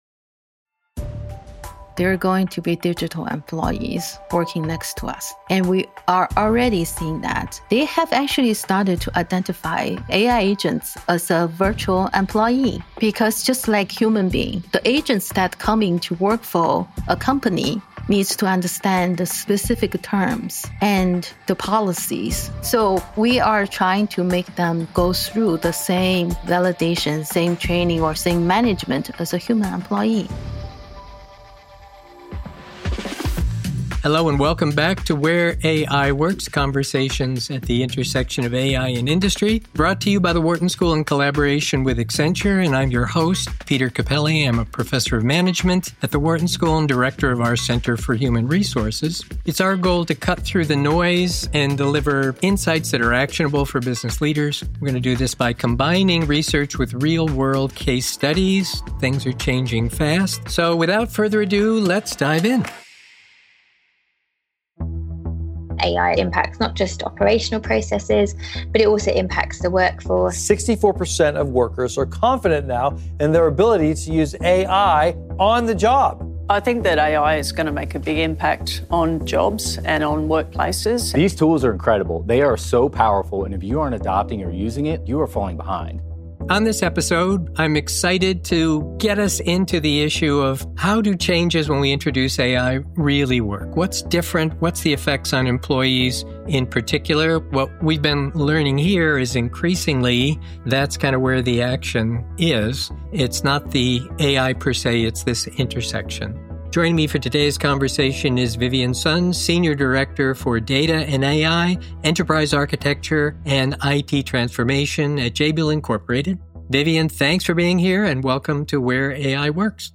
Where AI Works: Conversations at the Intersection of AI and Industry